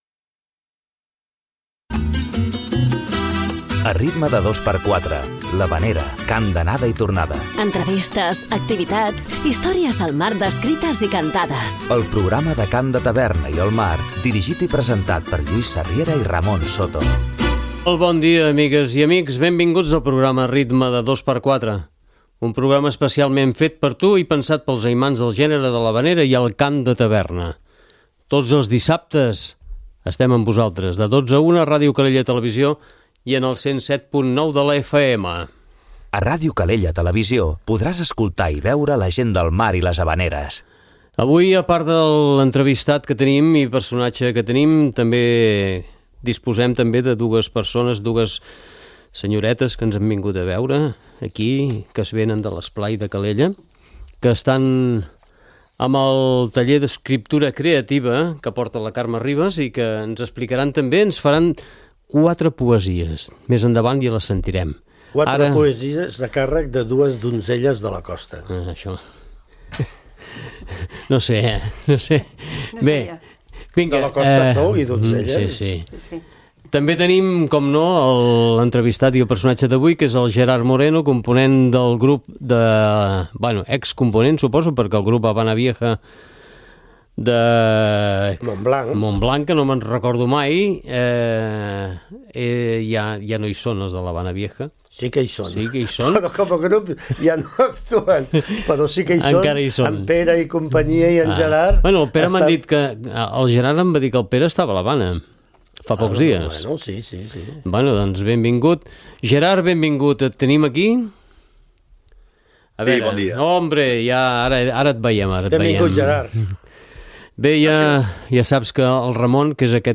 L'havanera, cant d'anada i tornada, entrevistes, activitats, històries del mar descrites i cantades; el programa del cant de taverna i el mar.